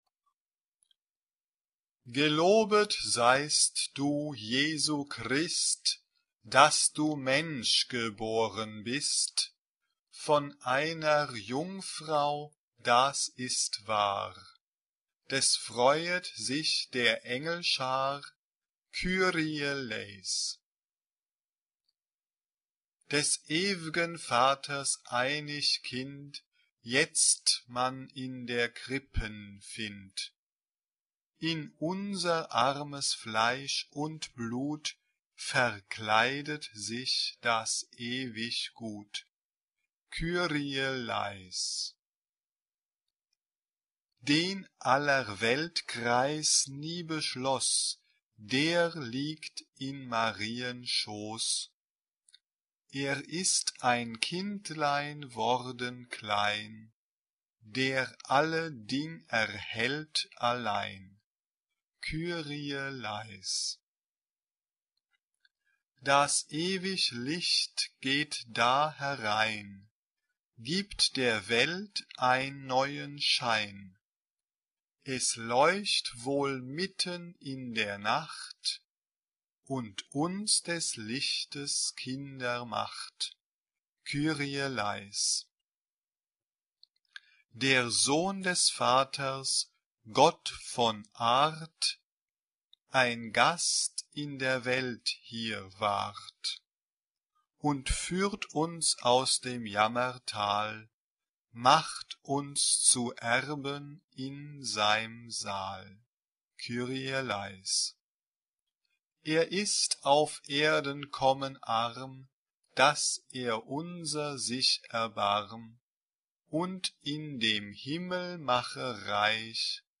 SATB (4 voices mixed) ; Full score.
Christmas song.
Genre-Style-Form: Sacred ; Christmas song Mood of the piece: festive Type of Choir: SATB (4 mixed voices )
Tonality: modal